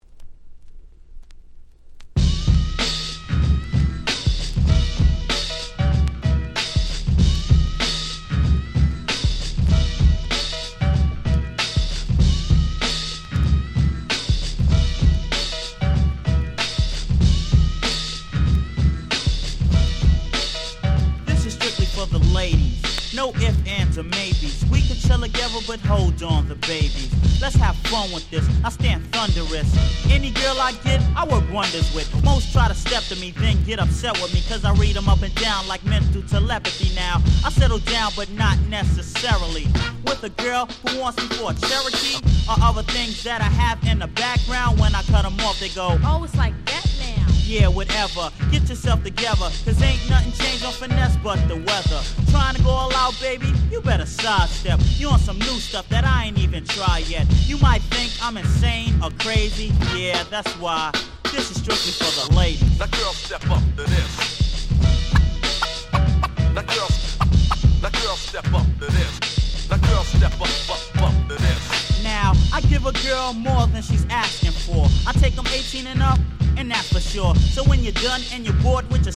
90' Super Hip Hop Classics !!